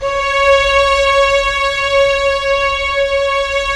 Index of /90_sSampleCDs/Roland L-CD702/VOL-1/STR_Symphonic/STR_Symph. Slow